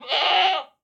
Minecraft Version Minecraft Version snapshot Latest Release | Latest Snapshot snapshot / assets / minecraft / sounds / mob / goat / scream9.ogg Compare With Compare With Latest Release | Latest Snapshot
scream9.ogg